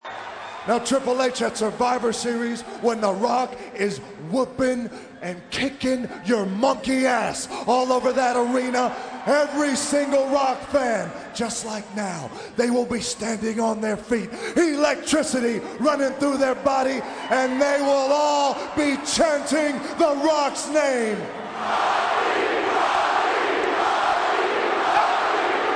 They are all chanting his name(Rocky,Rocky...)
chanting.mp3